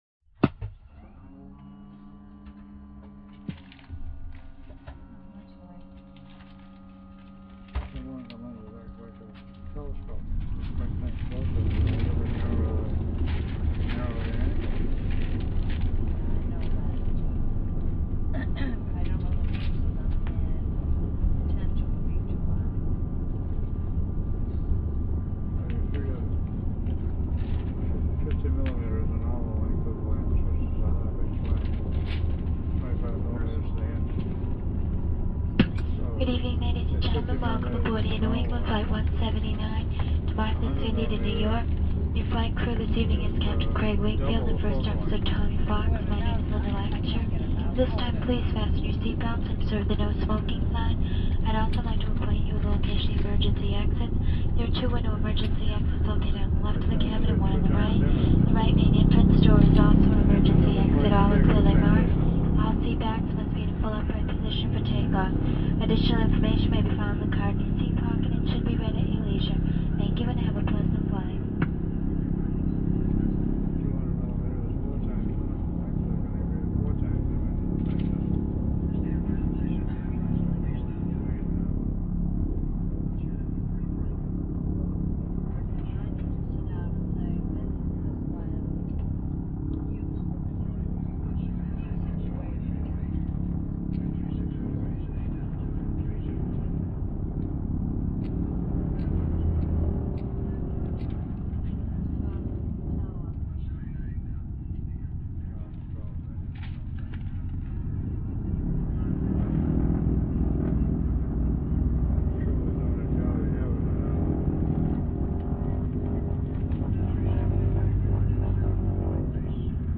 AIRCRAFT PROP PROP: INT: Passenger walla, P.A. announcements, taxi, take off, ascending, steady flight.